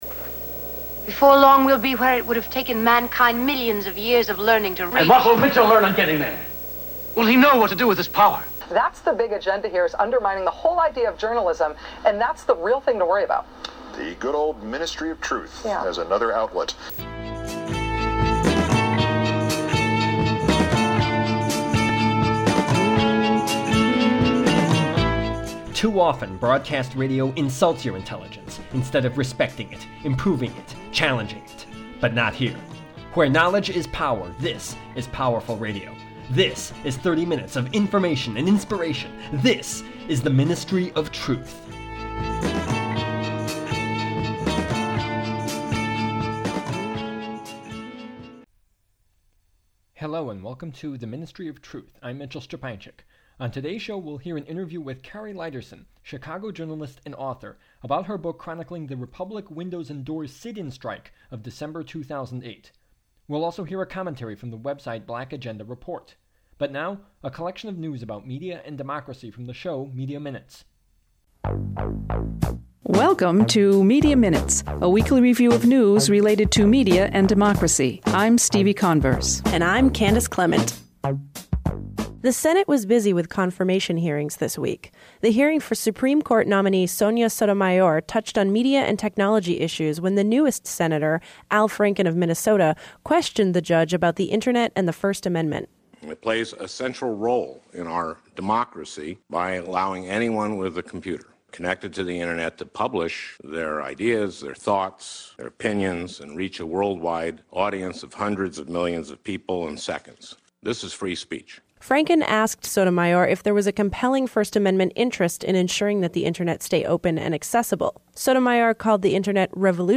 Tags: radio